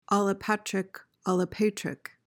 PRONUNCIATION:
(al-uh-PAT/PAYT-rik)